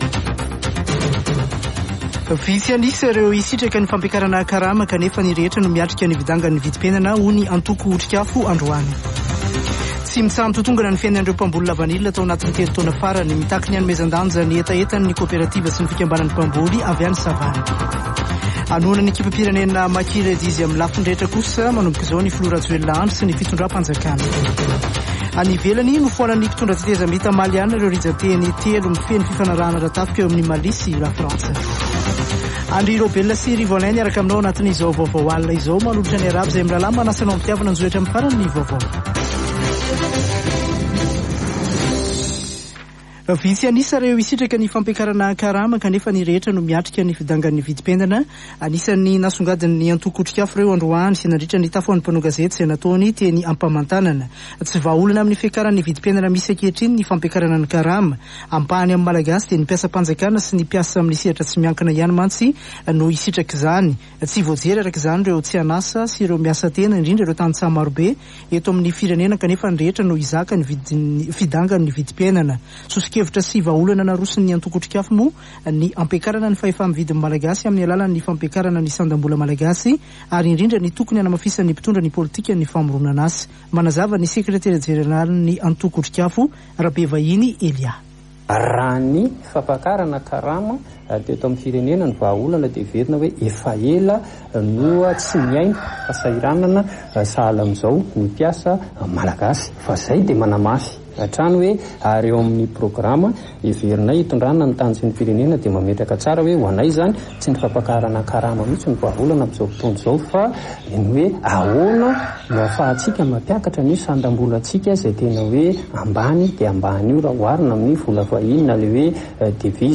[Vaovao hariva] Alarobia 4 mey 2022